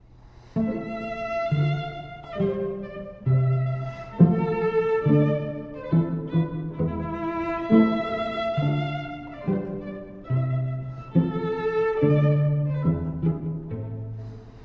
↑古い録音のため聴きづらいかもしれません！（以下同様）
Intermezzo: Allegretto con moto
～間奏曲：やや速く、動きを持って～
軽やかな間奏楽章です。